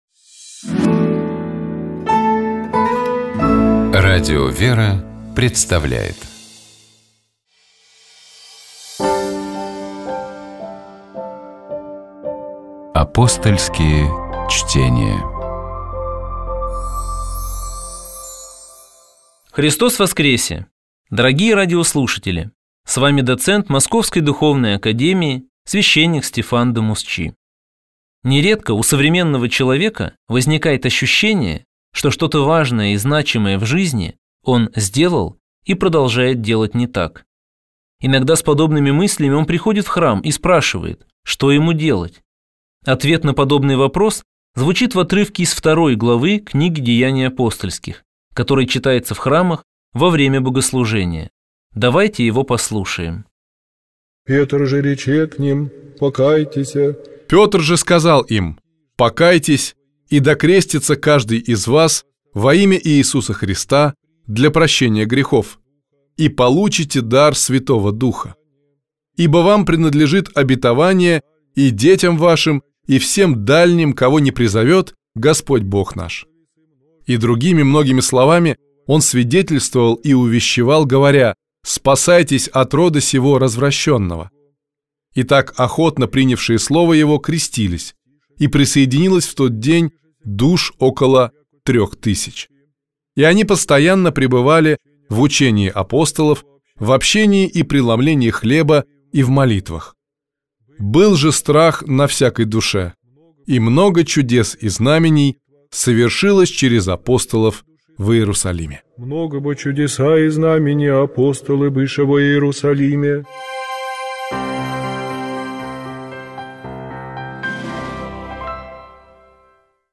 Давайте поразмышляем над текстом этой молитвы и послушаем её отдельными фрагментами в исполнении сестёр Орского Иверского женского монастыря.